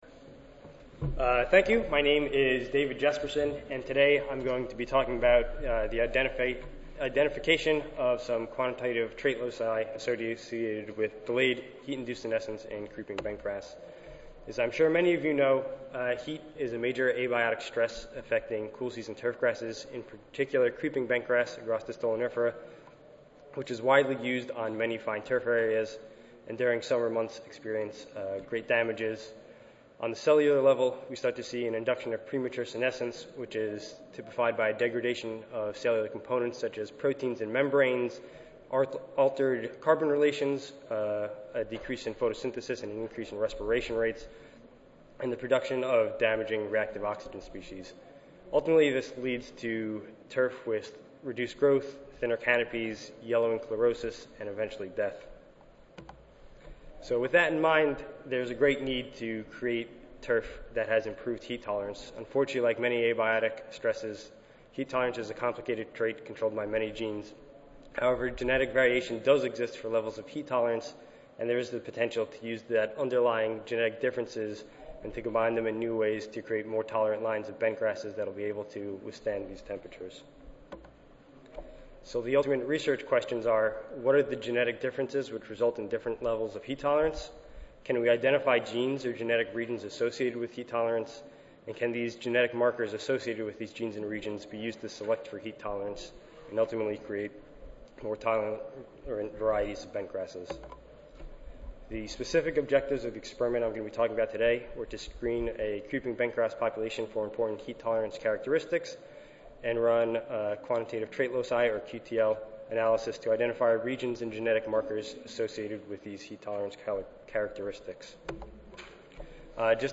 Rutgers University Audio File Recorded Presentation